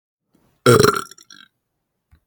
ITT we post our burps